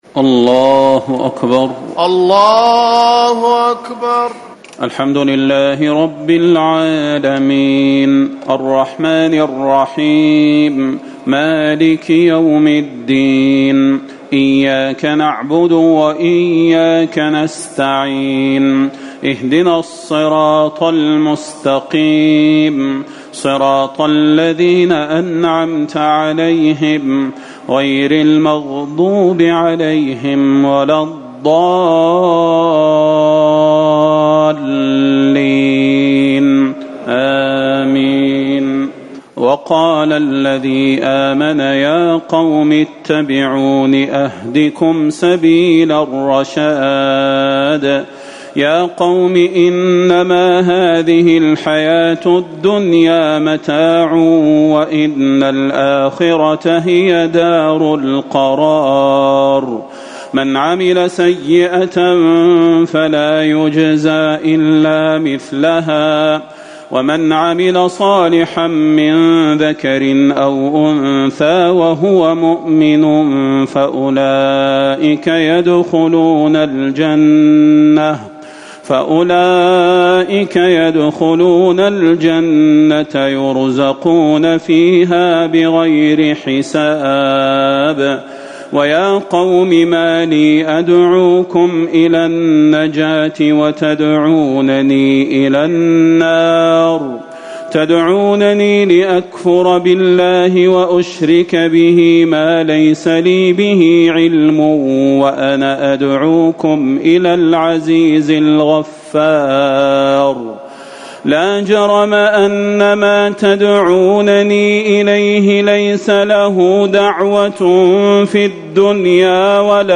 تراويح ٢٣ رمضان ١٤٤٠ من سورة غافر ٣٨ - فصلت ٤٥ > تراويح الحرم النبوي عام 1440 🕌 > التراويح - تلاوات الحرمين